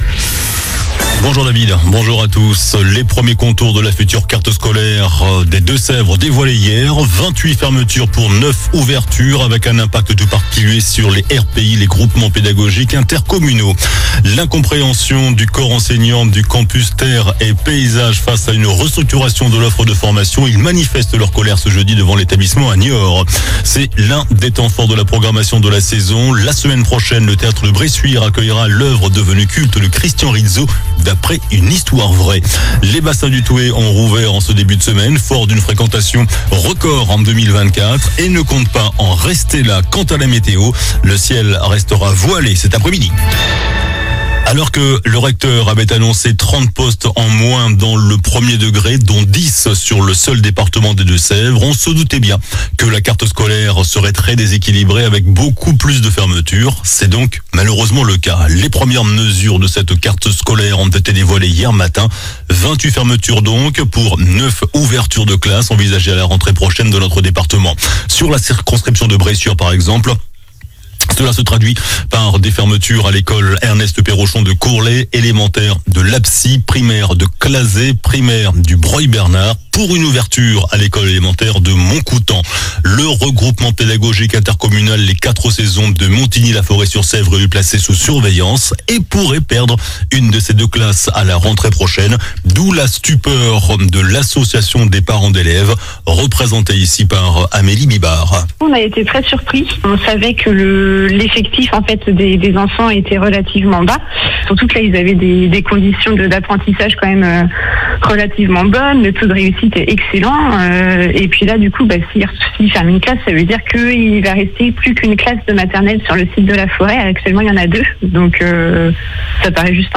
JOURNAL DU JEUDI 13 FEVRIER ( MIDI )